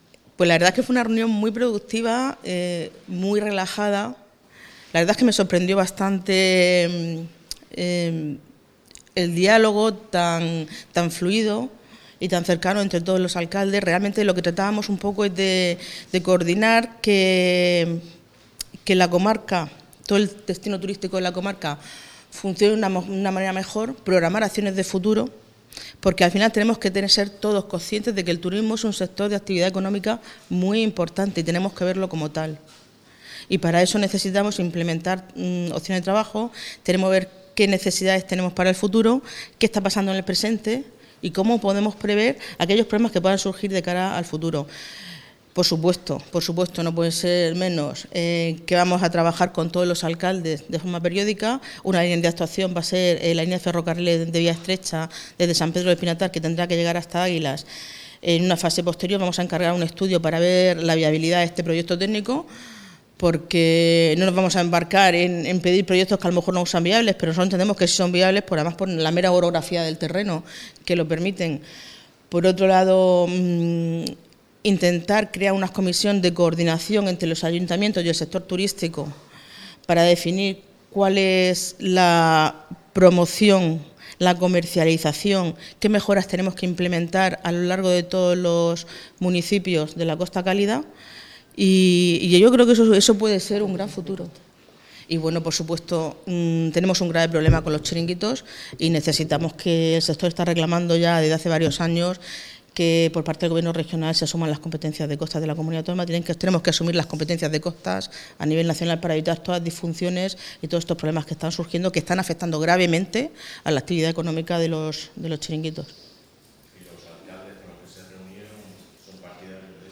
Audio: Declaraciones de la alcaldesa, Noelia Arroyo. (MP3 - 16,98 MB)